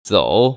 [zǒu]
조우